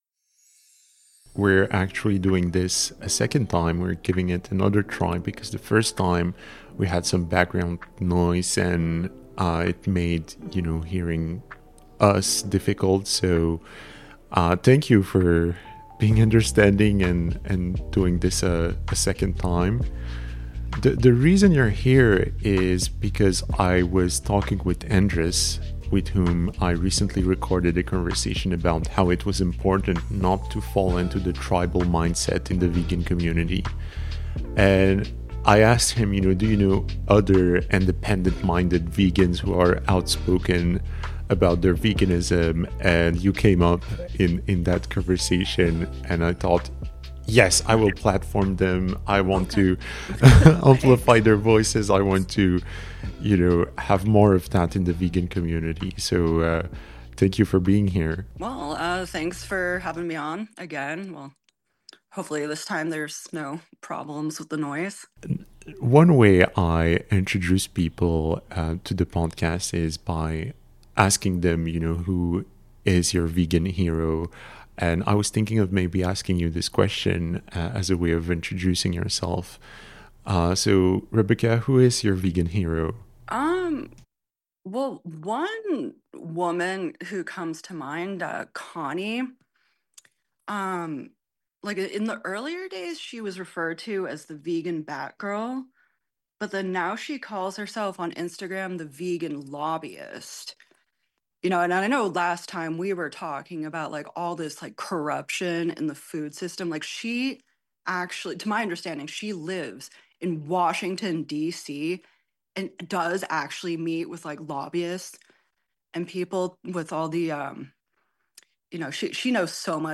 casual conversation